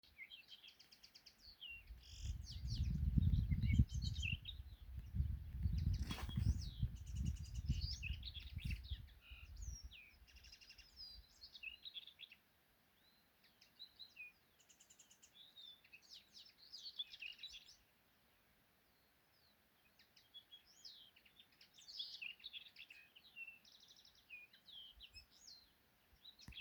Kaņepītis, Linaria cannabina
Administratīvā teritorijaDaugavpils novads
StatussDzied ligzdošanai piemērotā biotopā (D)